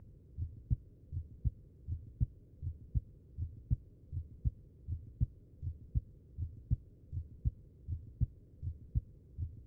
You are listening to a patient with normal heart sounds.
You are hearing the first and second heart sounds. The intensity of the first sound is greater than the second.